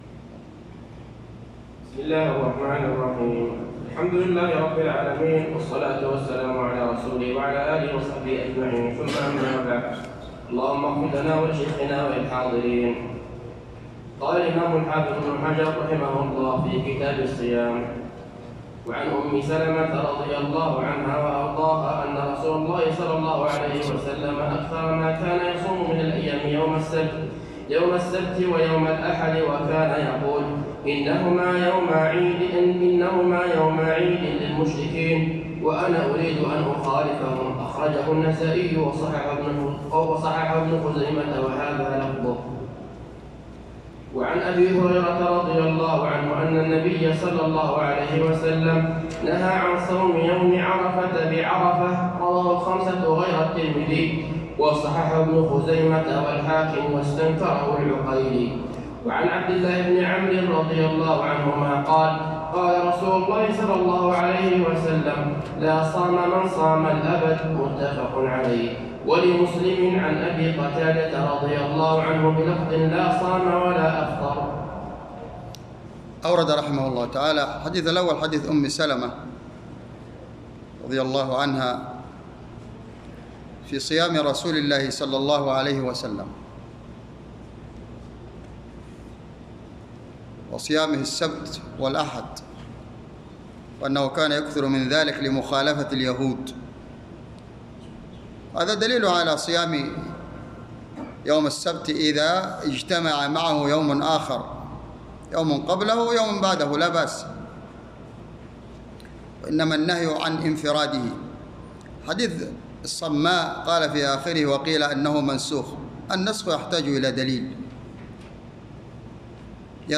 تسجيل الدورة العلمية الأولى في محافظة ضمد شرح كتاب الصيام من بلوغ المرام